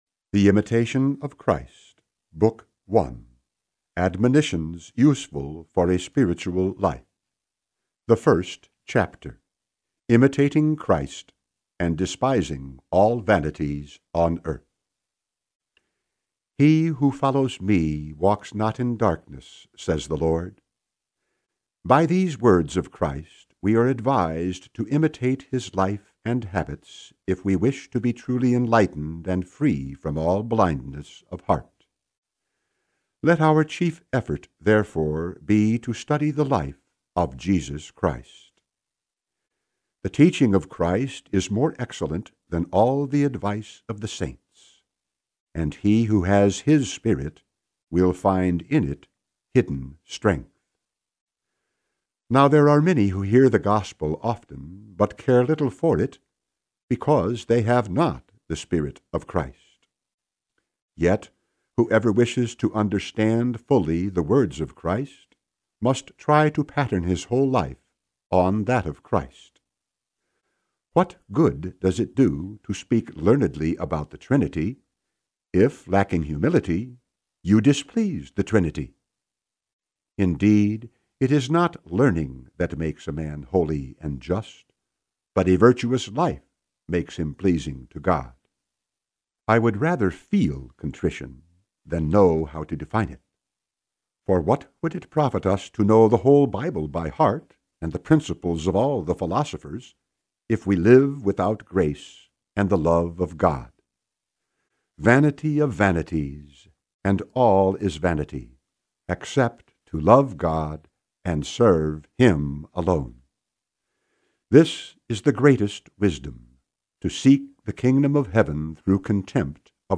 Genre: Spiritual Reading.